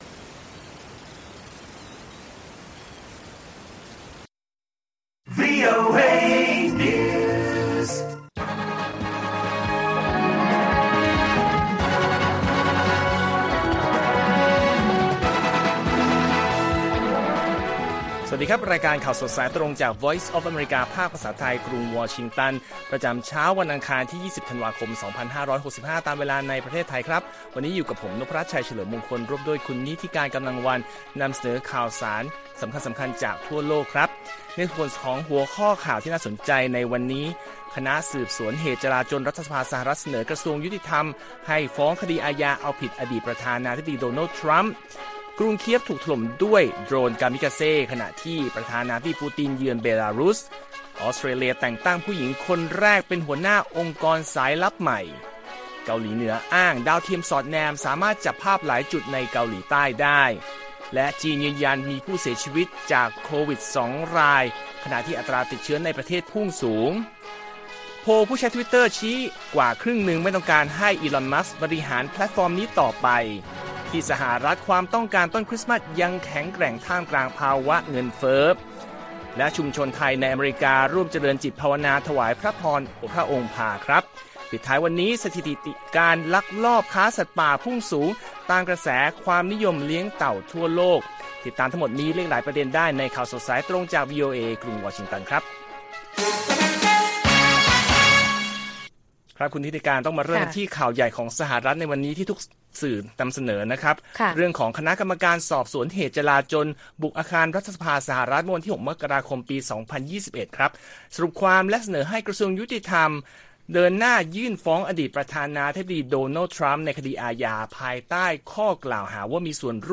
ข่าวสดสายตรงจากวีโอเอไทย อังคาร ที่ 20 ธ.ค. 65